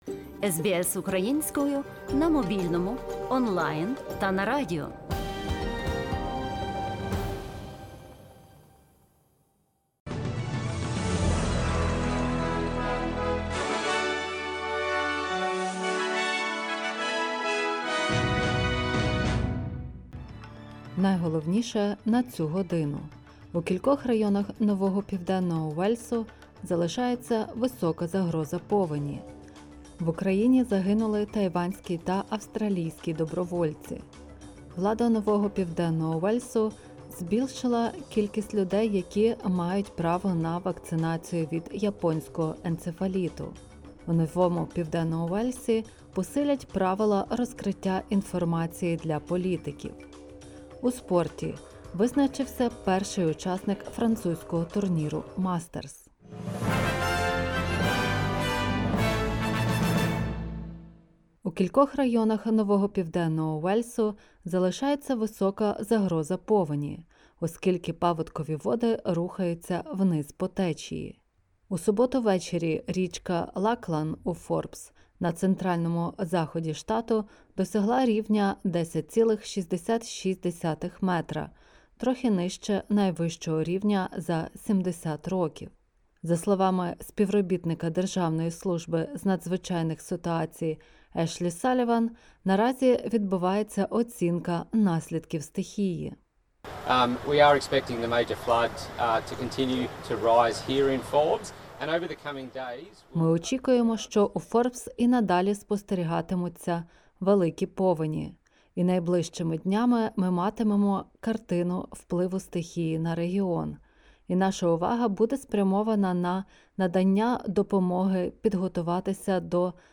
SBS news in Ukrainian – 06/11/2022